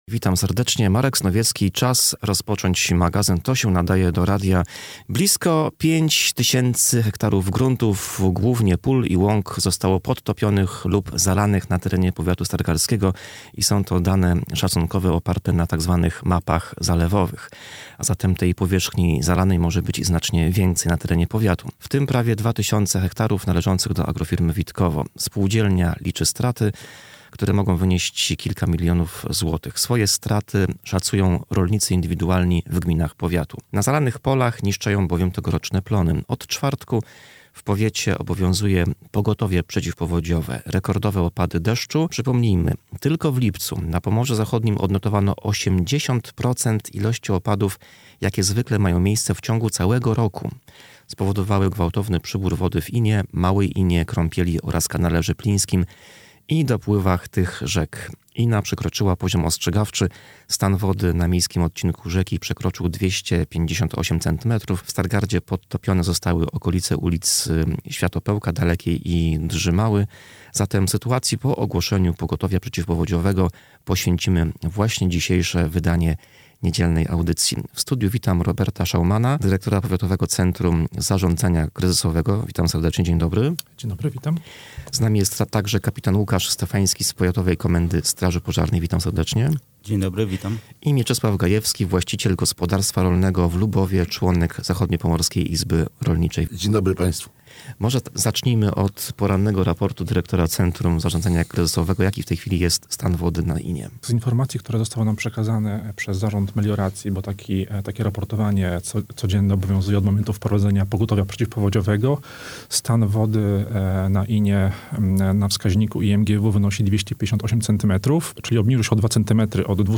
Na antenie wypowiadali się również rolnicy, którzy nie mogą wjechać na podtopione pola. Marka Subocza, wicewojewodę zachodniopomorskiego pytamy też, czy są podstawy do wszczęcia procedury, zmierzającej do ogłoszenia klęski żywiołowej w regionie.